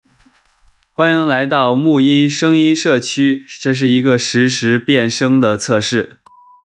原声：